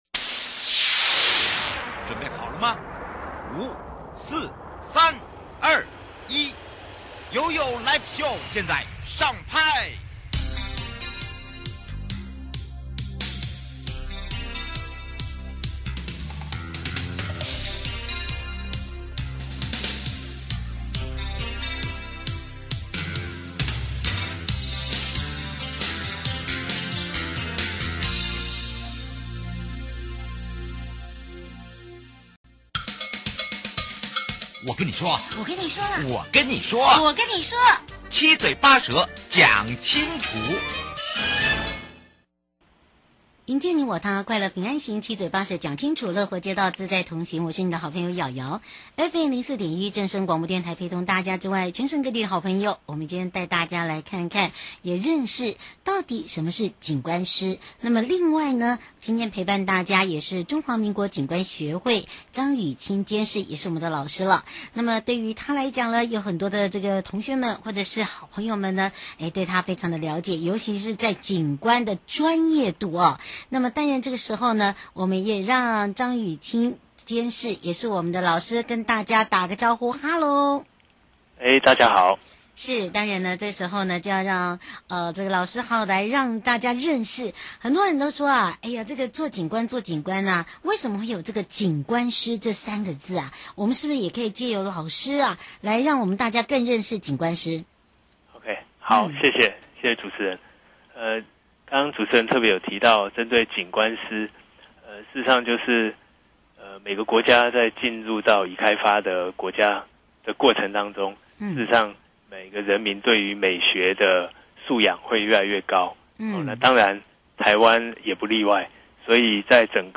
受訪者： 營建你我他 快樂平安行-提及WHO/聯合國國際衛生組織，除了傳染病防治為其重要任務之外，另一項鮮為人